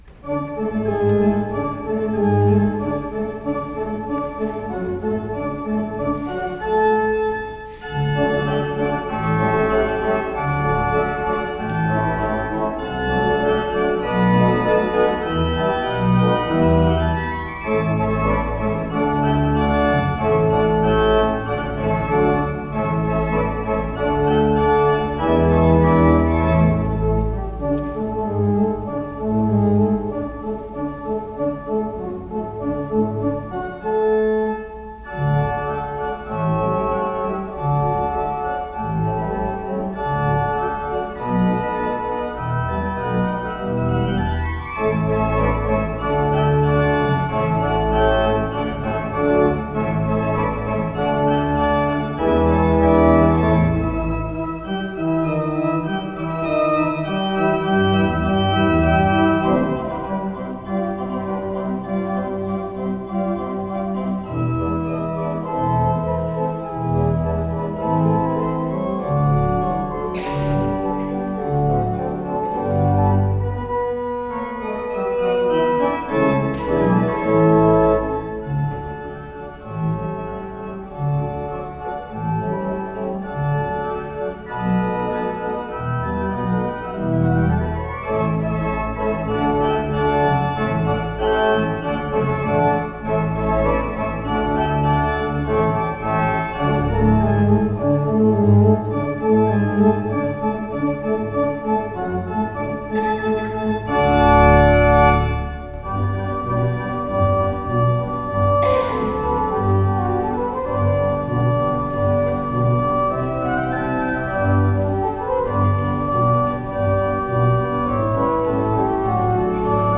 Below is a selection he performed with the majestic Skinner Organ in our church on April 25, 1999: